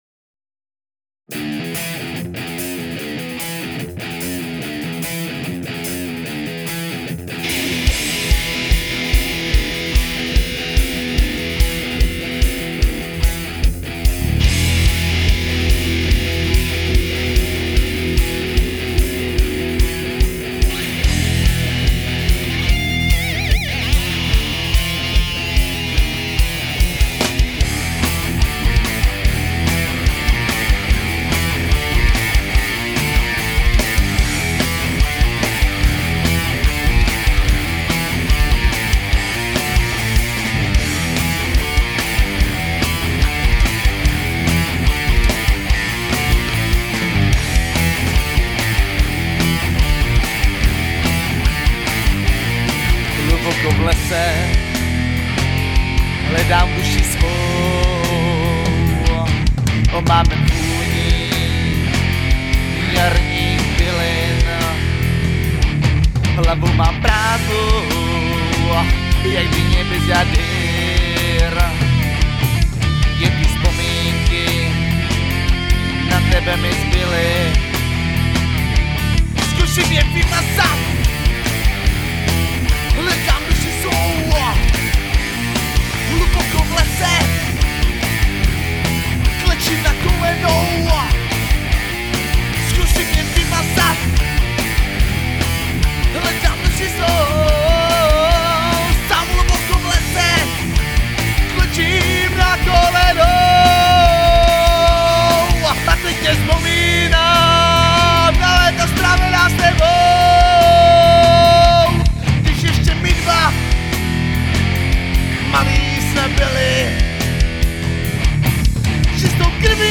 Nahráno to bylo za výjimečných okolností, zcela zdarma a pochopitelně ve spěchu, což jde i hodně slyšet.
Zpěvák má hlas poměrně zajímavej, ale zvláště v dlouhých tónech špatně intonuje (kolísá) a bacha na obrácené přízvuky (v češtině by měl být přízvuk vždy na první slabiku).
Muzika zní seriózně, kytary štěkaj dž dž dž dž a on do toho pomalu jódluje.